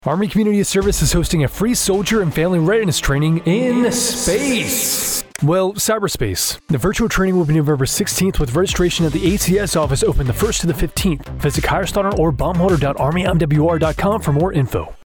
This is a 15-second radio advertisement publicizing a virtual Soldier and family readiness workshop taking place Nov. 16. The training is slated to be complete via Microsoft Teams.